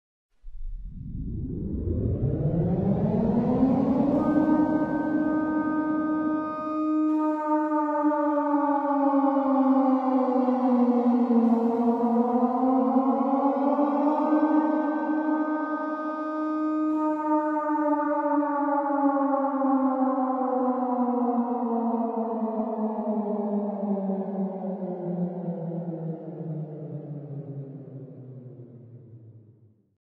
World War Siren Sound Effect Free Download
World War Siren